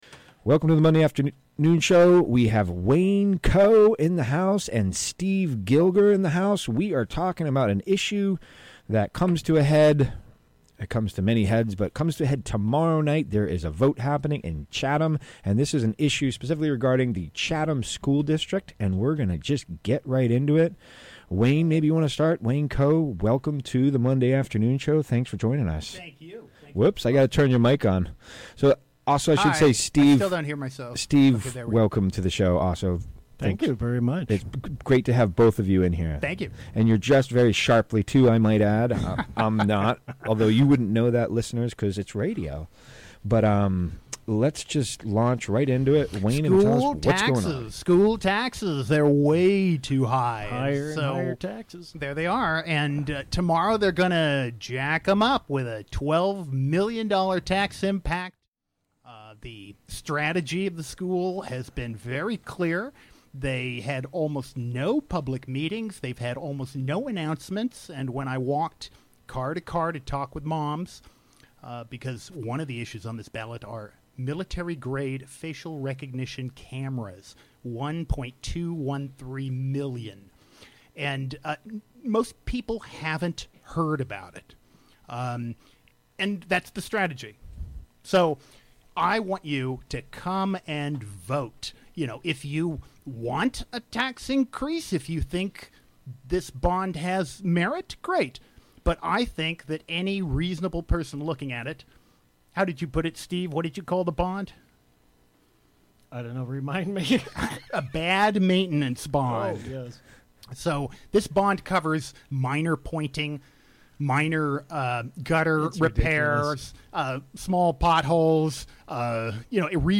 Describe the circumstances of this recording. Recorded live on the WGXC Afternoon Show on Monday, May 14, 2018.